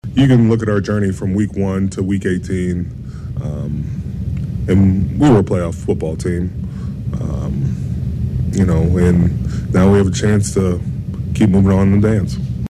nws0584-cam-heyward-what-a-journey.mp3